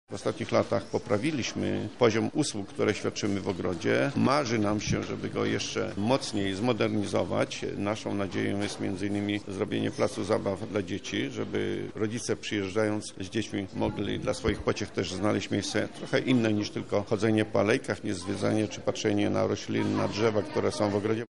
– mówi prof. Stanisław Michałowski, rektor uczelni.